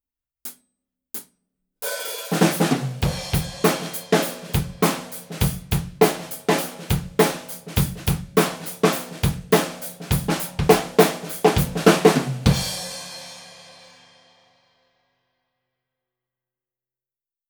AKG C451B一本のみでドラムを録ってみました。
すべて、EQはしていません。
①アンビエンス 約２ｍ
ドラムから約２ｍ離した位置にマイキングしました。
スモールダイヤフラムの特徴でもある、低音が少ないすっきりとしたサウンドですね！